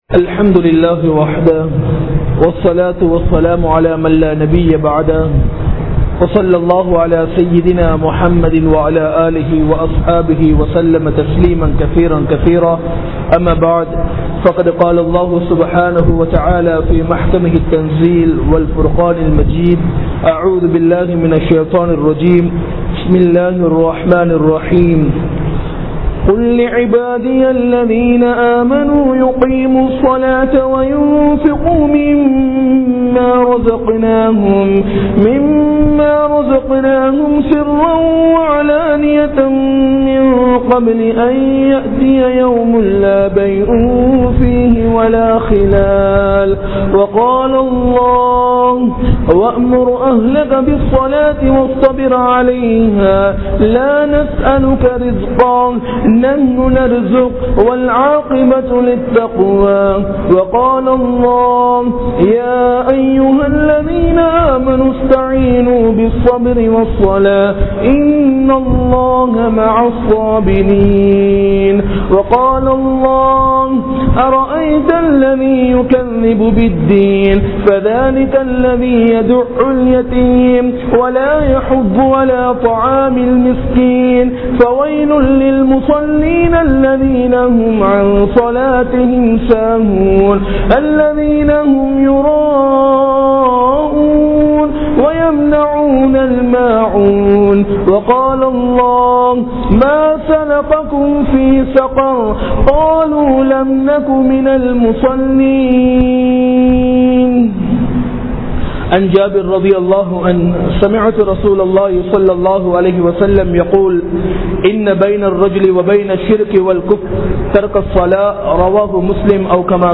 Marumai Naalil Keatka Padum Muthal Kealvi Enna? (மறுமை நாளில் கேட்கப்படும் முதல் கேள்வி என்ன?) | Audio Bayans | All Ceylon Muslim Youth Community | Addalaichenai
Colombo 04, Majma Ul Khairah Jumua Masjith (Nimal Road)